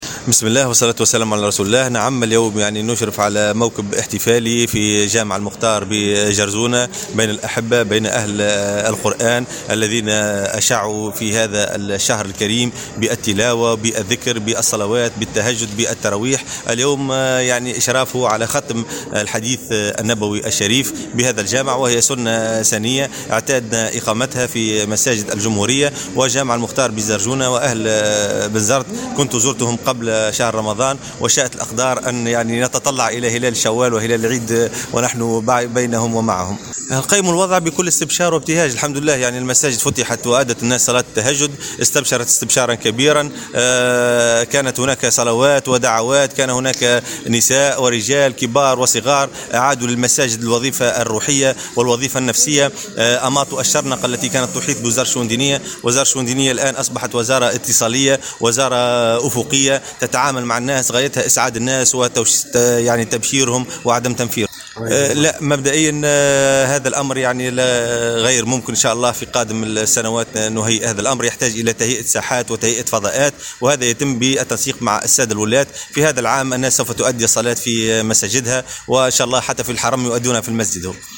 En marge d’une visite effectuée hier au gouvernorat de Bizerte, le ministre des Affaires Religieuses Brahim Chaïbi a déclaré que la prière de l’Aïd El Fitr pour cette année sera accomplie dans les mosquées.